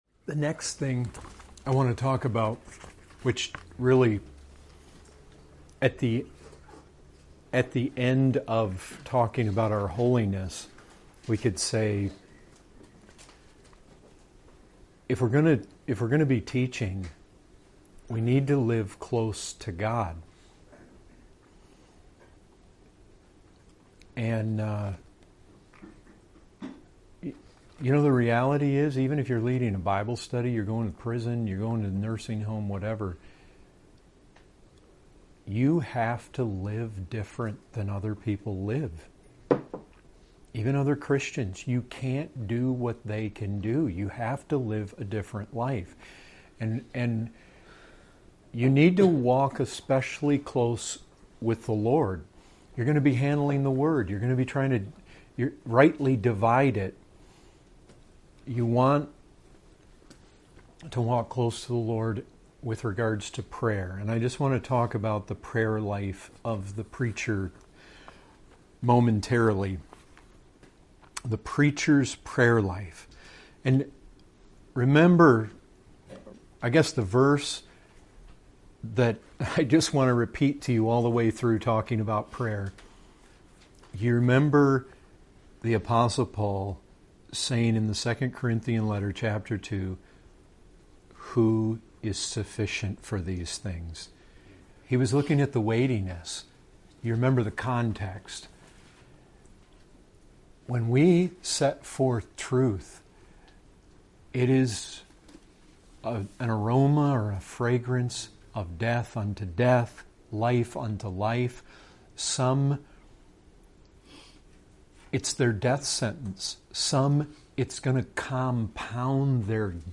Category: Bible Studies